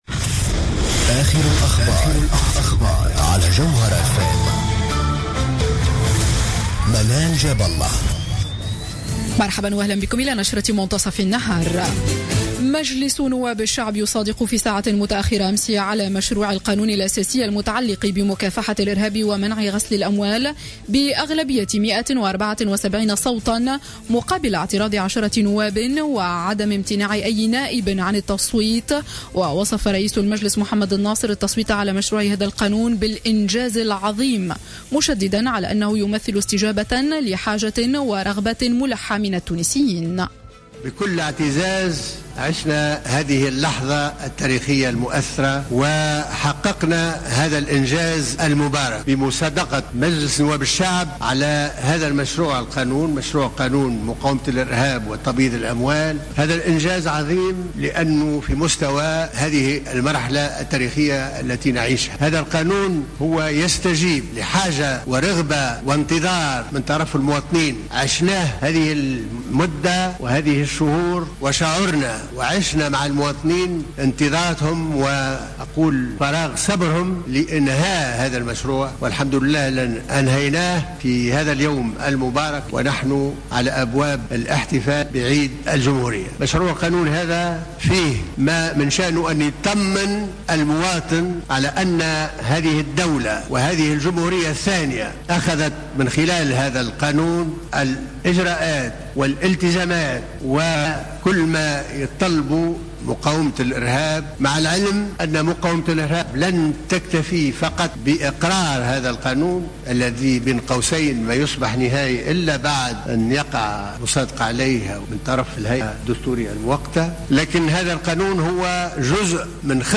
نشرة أخبار منتصف النهار ليوم السبت 25 جويلية 2015